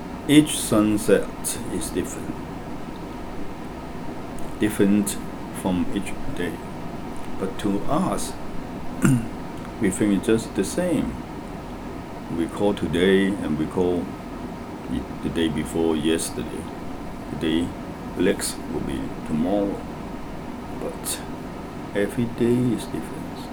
S1 = Hong Kong male S2 = Malaysian female
There is no [l] on the end of call . The word next is pronounced with an initial [l].